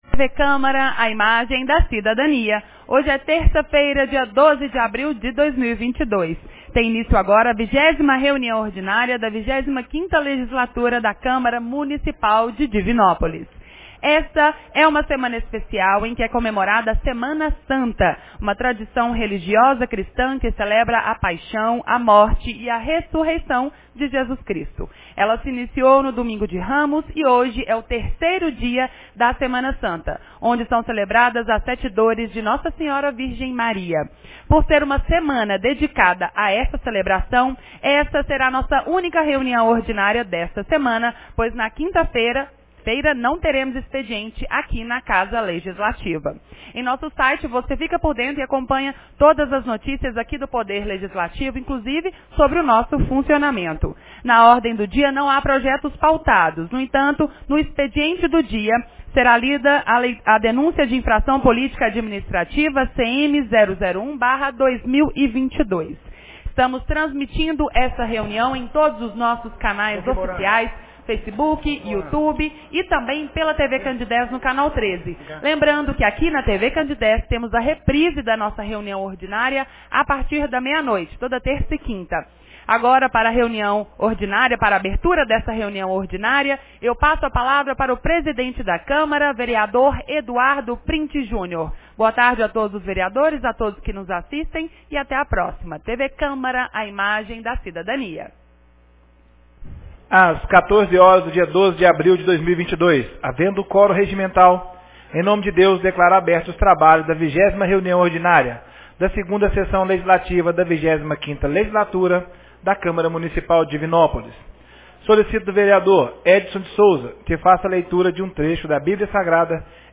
20ª Reunião Ordinária 12 de abril de 2022